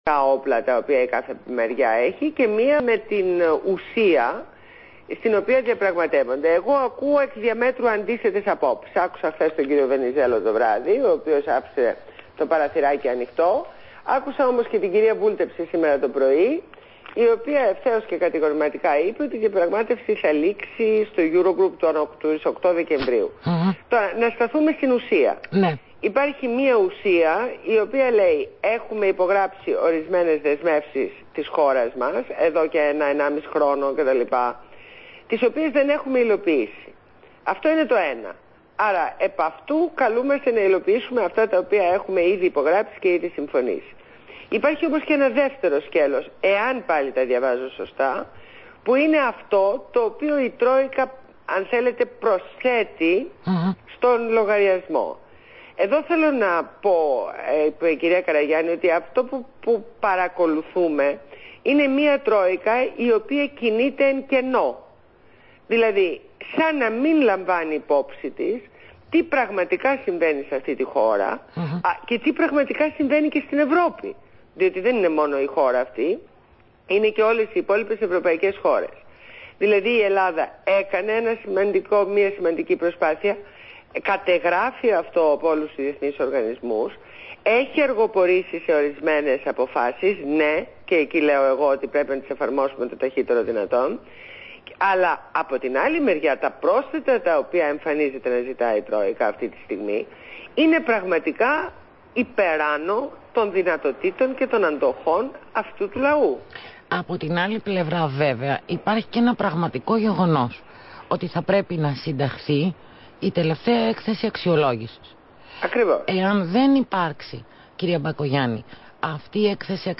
Συνέντευξη στο ραδιόφωνο Αθήνα 9,84FM